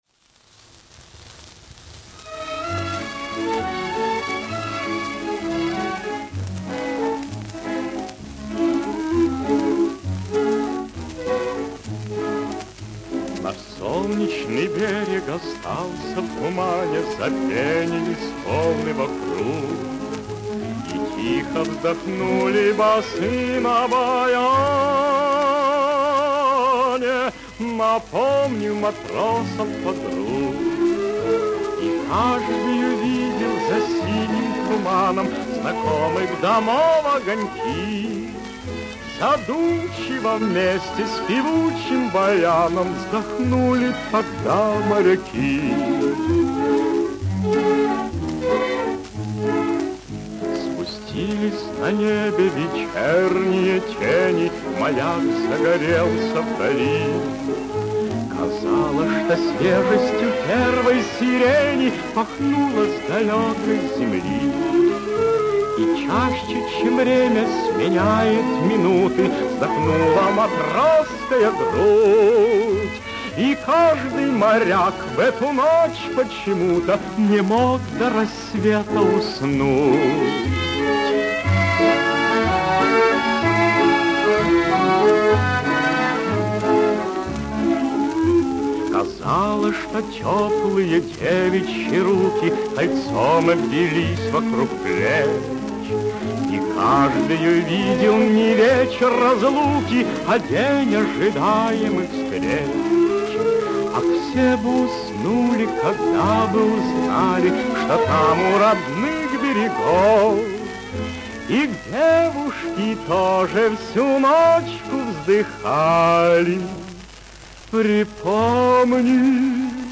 Главная / Computer & mobile / Мелодии / Патриотические песни
Как следует из названия, мелодичная и душевная песня о море